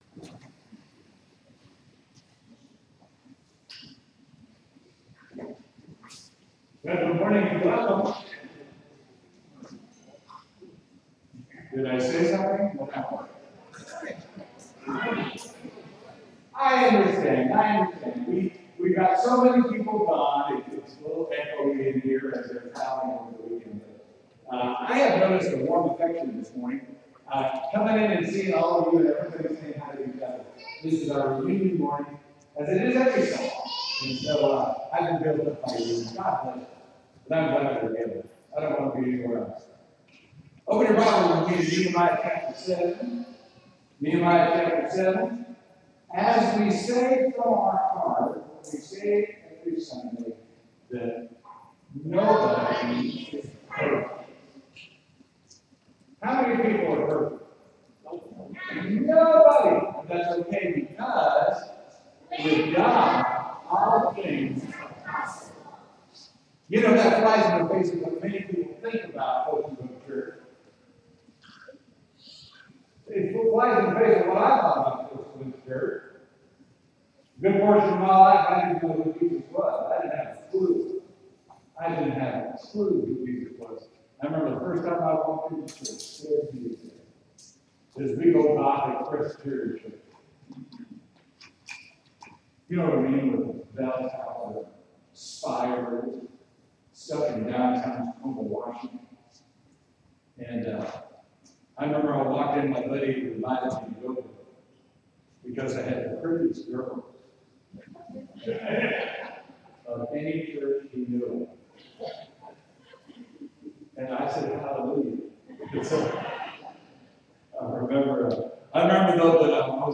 Sermon: Nehemiah 7